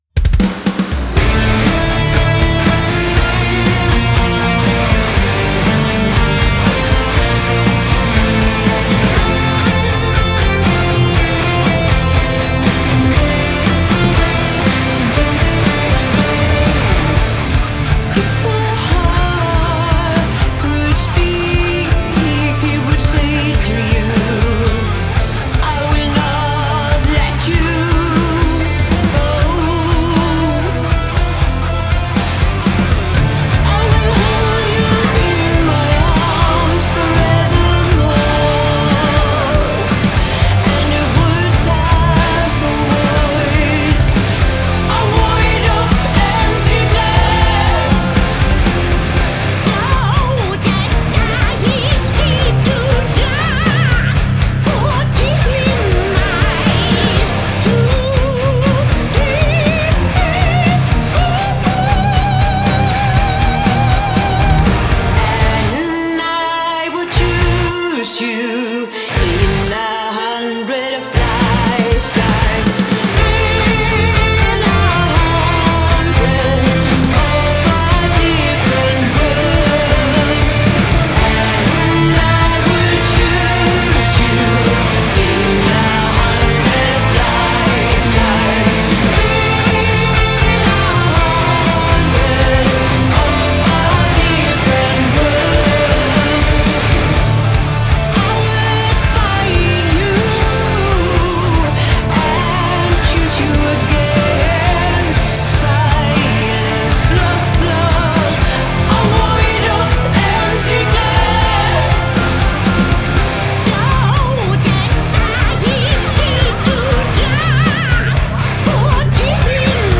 آهنگ پاپ خارجی
موزیک آلترناتیو متال راک آلترناتیو